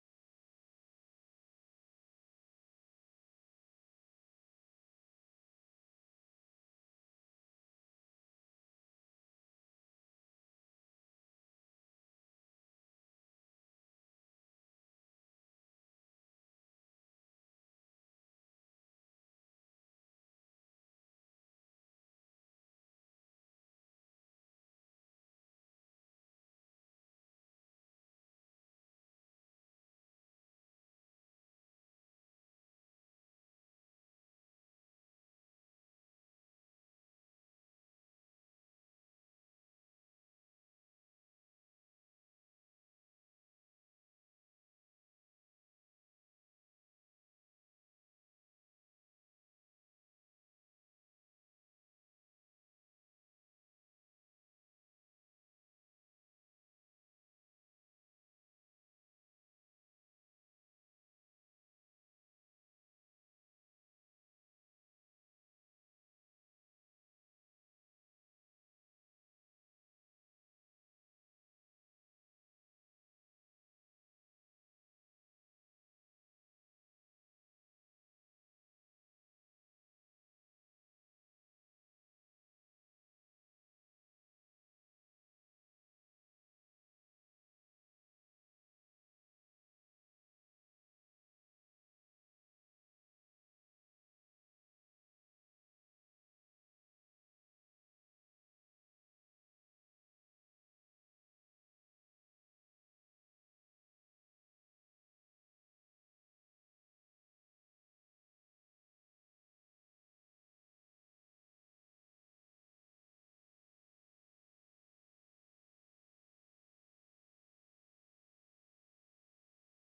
December 26, 2021 (Morning Worship)